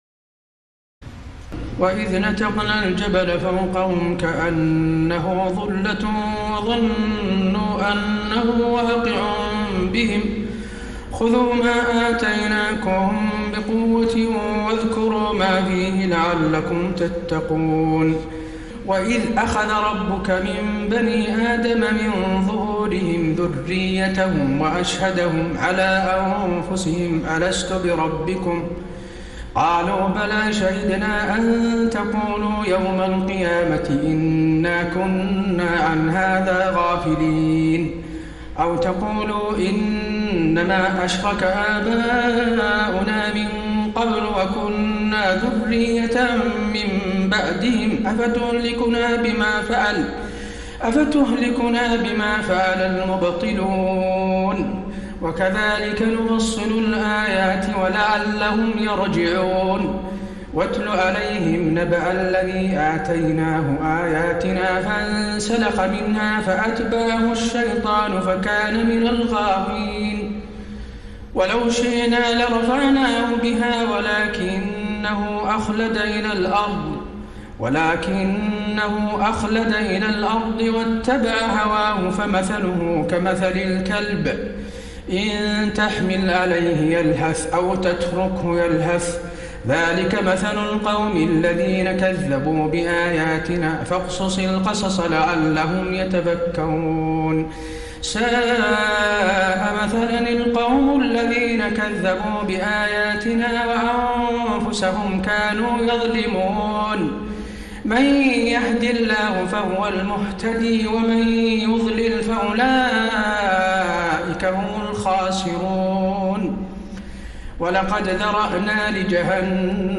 تراويح الليلة التاسعة رمضان 1433هـ من سورتي الأعراف (171-206) والأنفال (1-60) Taraweeh 9 st night Ramadan 1433H from Surah Al-A’raf and Al-Anfal > تراويح الحرم النبوي عام 1433 🕌 > التراويح - تلاوات الحرمين